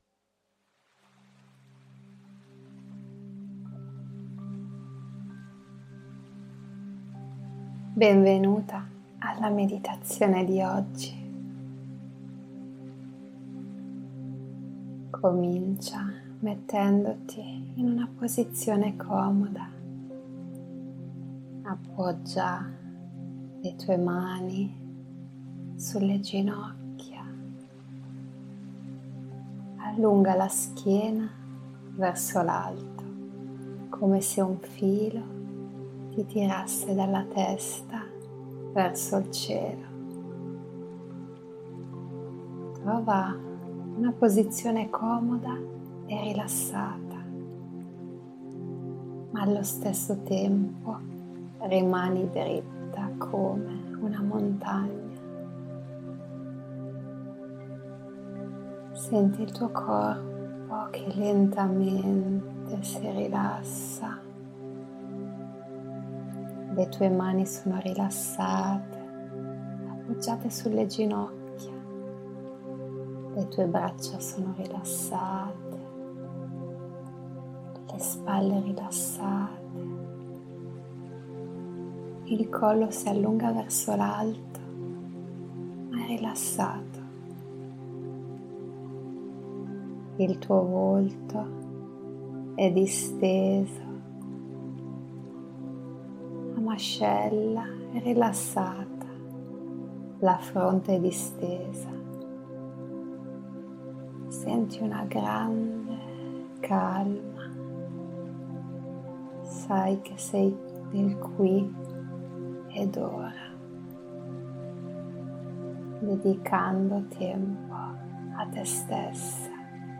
In questo articolo ti parlo della tua vocina interiore e ti regalo una meditazione guidata per calmare i pensieri che minano la tua tranquillità ed autostima, cominciando a coltivare amore per te stessa.
Meditazione-delle-parole-gentili.mp3